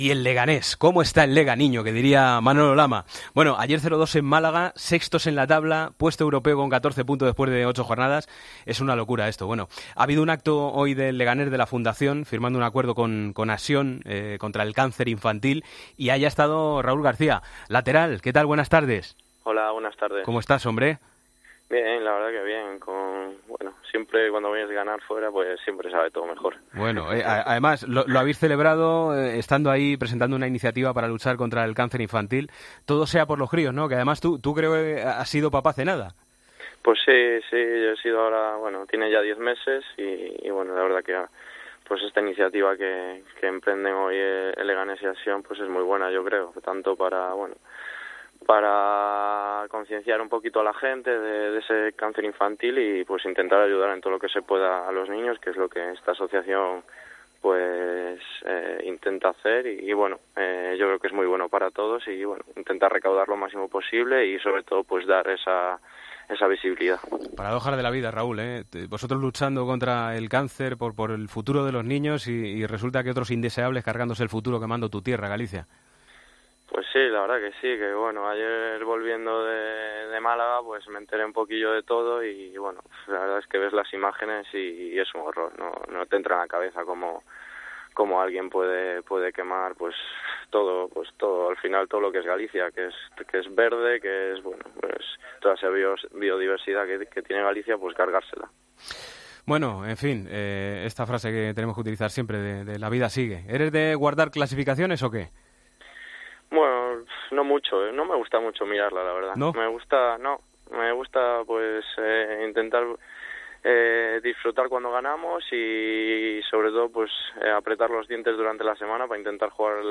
Hablamos con el jugador del Leganés y su buen momento en LaLiga: "Ayer volviendo de Málaga me enteré de lo que ocurría en mi tierra y es horrible todo.